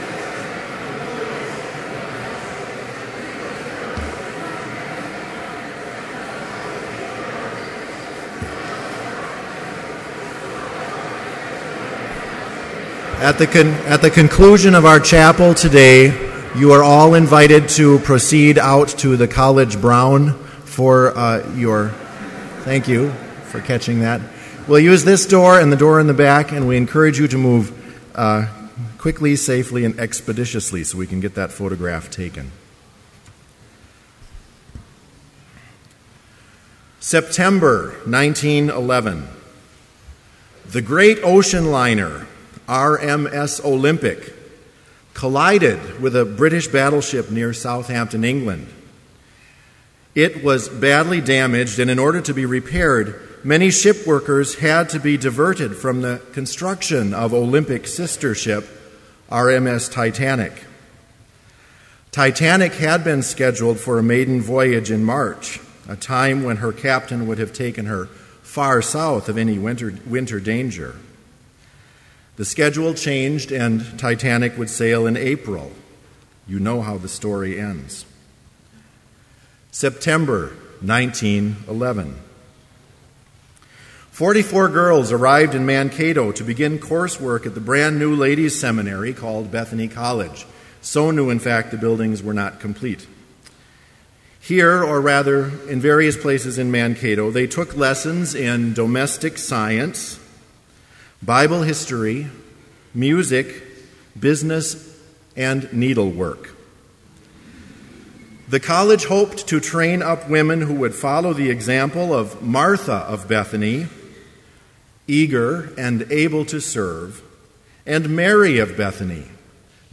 Chapel worship service held on August 29, 2012, BLC Trinity Chapel, Mankato, Minnesota,
Complete service audio for Chapel - August 29, 2012